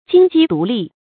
金雞獨立 注音： ㄐㄧㄣ ㄐㄧ ㄉㄨˊ ㄌㄧˋ 讀音讀法： 意思解釋： 中國武術的一種姿式。